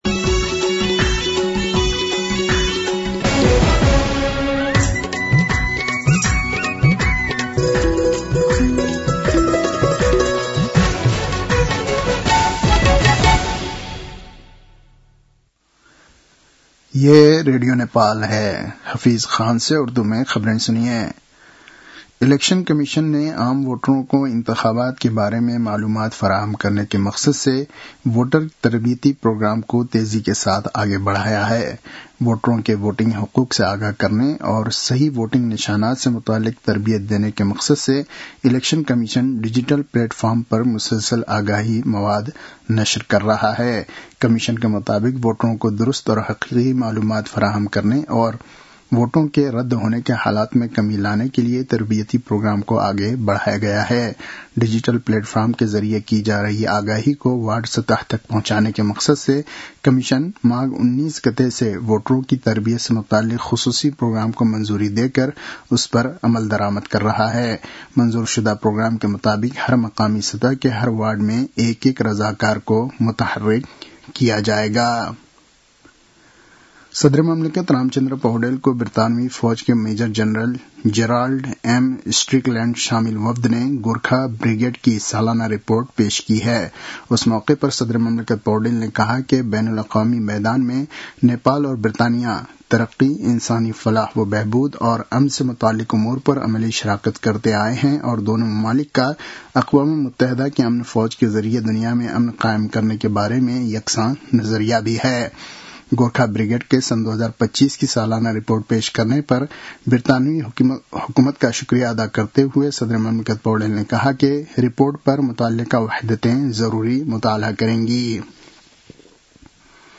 उर्दु भाषामा समाचार : २६ माघ , २०८२